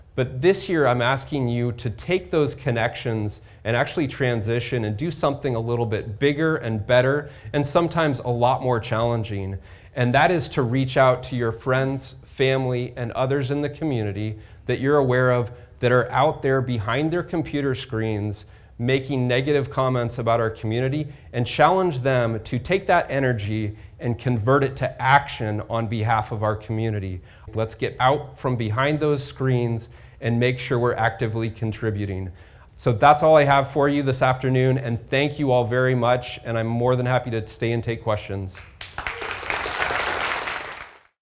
West delivers State of the City address